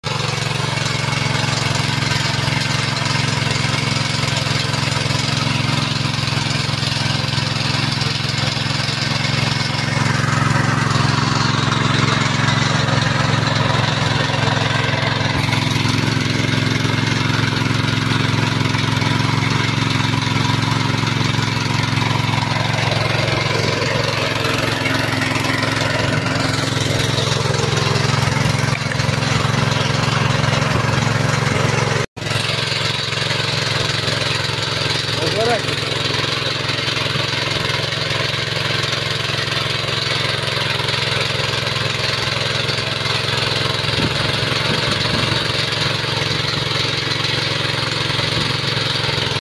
Мотоблок вспахивает землю